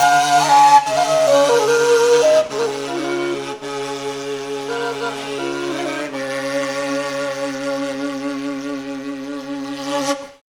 TIBETDRON2-L.wav